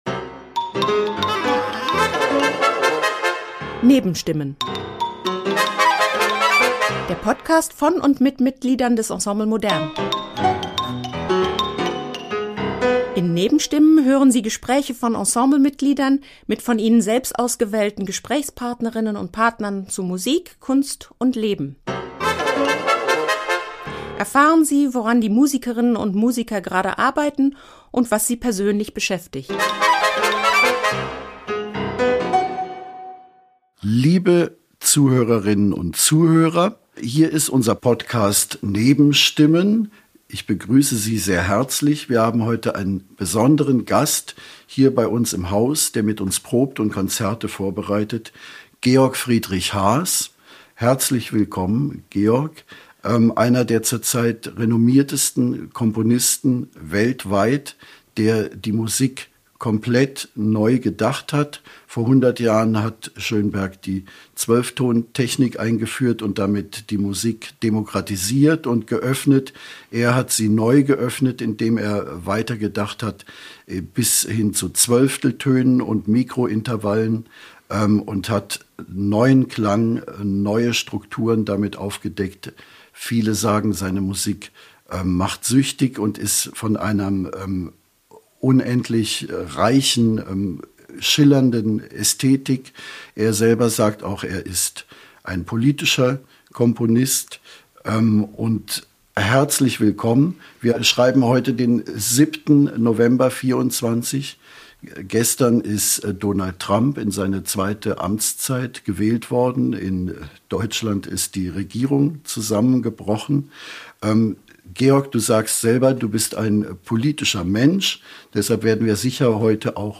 Beschreibung vor 1 Jahr In “Nebenstimmen” hören Sie Gespräche von Ensemble-Mitgliedern mit von ihnen selbst ausgewählten Gesprächspartnerinnen zu Musik, Kunst und Leben.